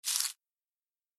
دانلود آهنگ جنگل 25 از افکت صوتی طبیعت و محیط
دانلود صدای جنگل 25 از ساعد نیوز با لینک مستقیم و کیفیت بالا
جلوه های صوتی